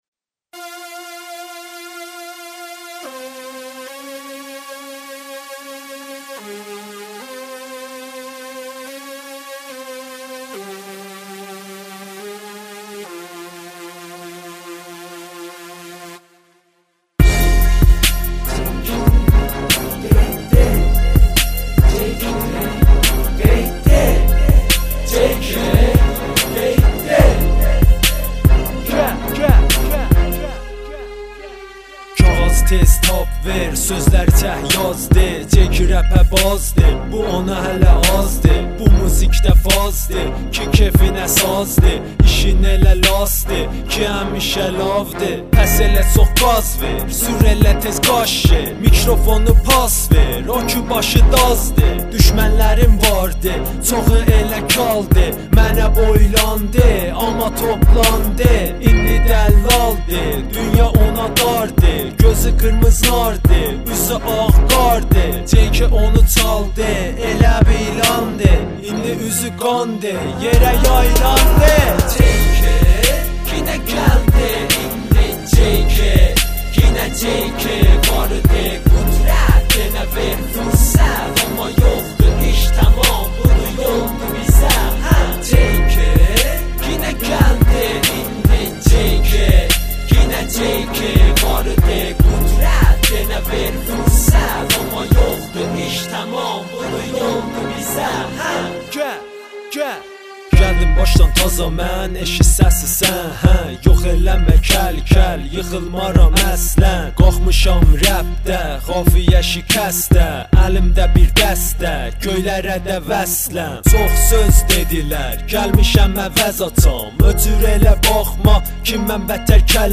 رپ ماهنی
rəp mahnı